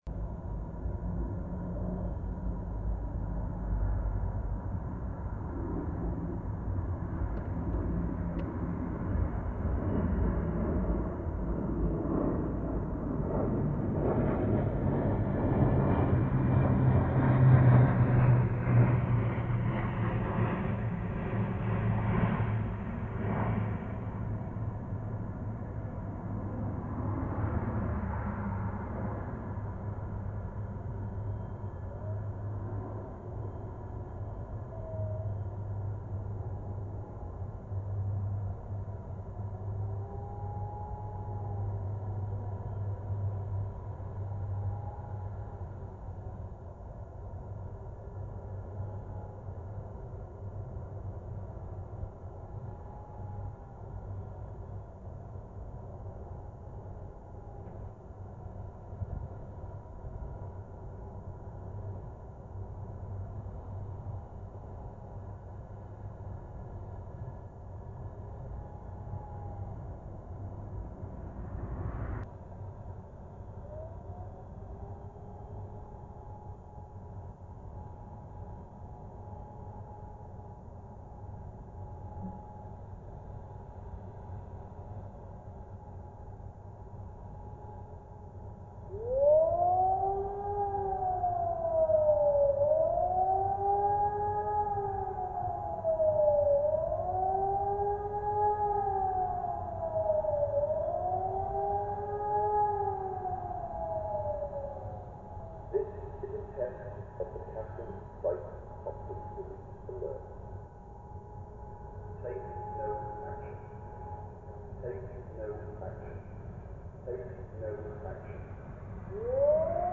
Billingham On Tees, test of chemical factory sirens
I went down one day and parked my car as close as possible. The recording starts with a plane going overhead, then the distant sirens and finally the nearby sirens complete with announcement.